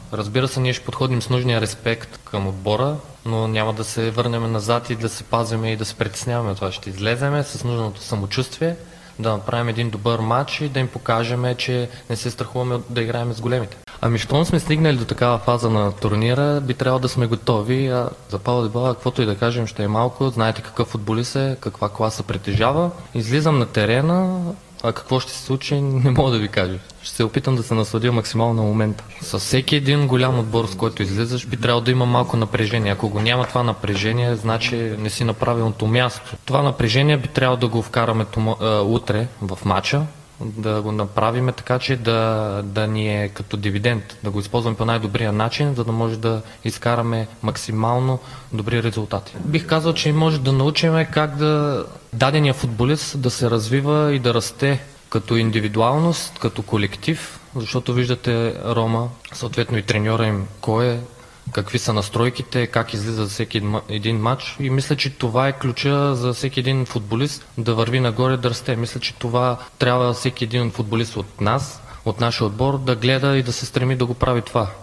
Капитанът на Лудогорец Антон Недялков говори пред медиите на официалната пресконференция преди мача срещу Рома.